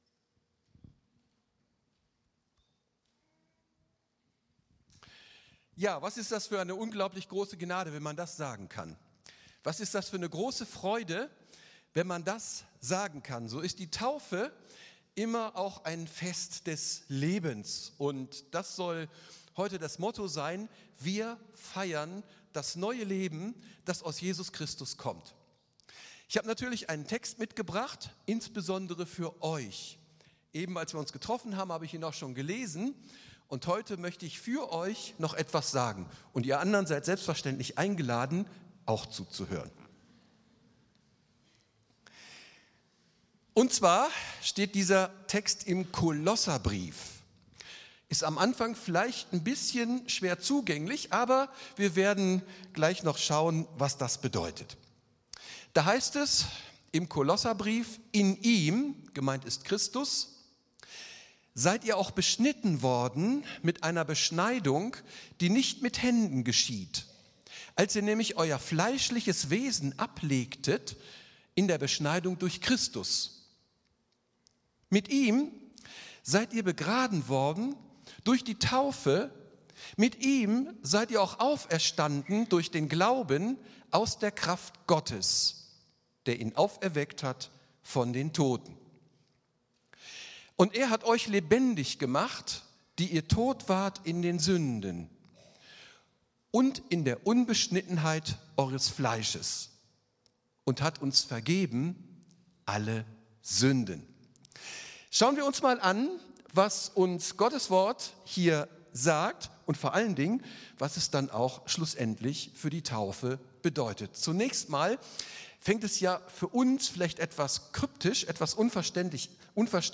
Taufgottesdienst – Gnadenkirche Villingen-Schwenningen
Taufgottesdienst